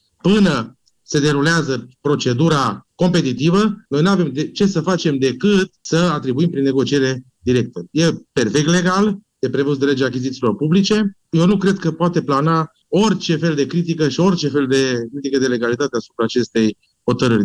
Consilierul local Sergiu Papuc a explicat că această variantă este legală: